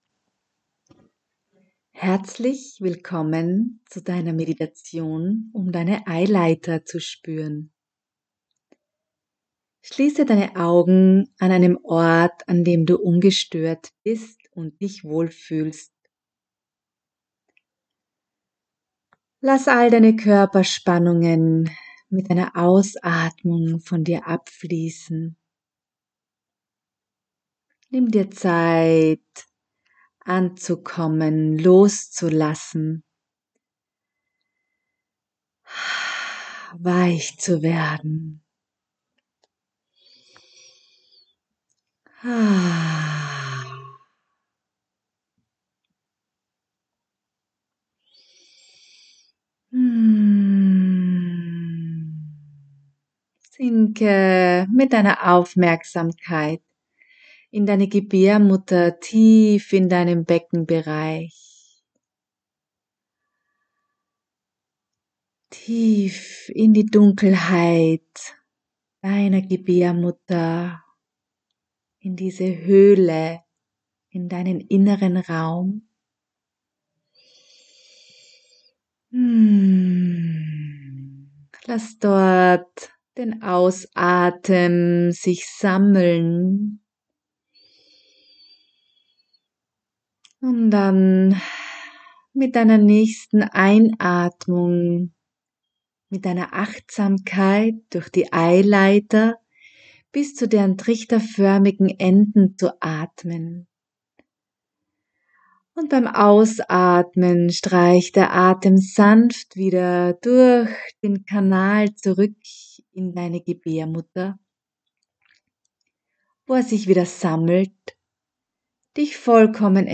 Audio-Meditationen aus dem Buch
Meditation-Eileiter-spueren.mp3